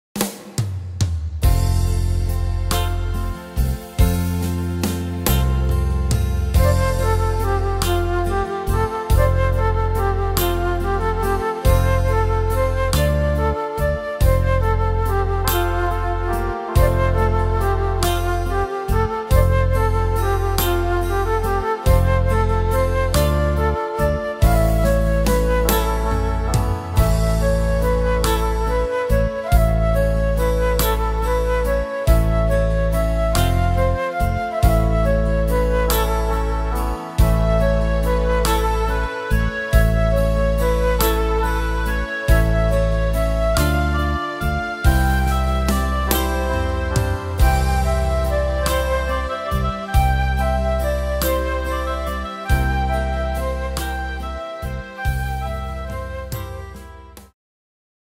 Tempo: 47 / Tonart: F-Dur